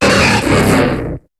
Cri d'Élecsprint dans Pokémon HOME.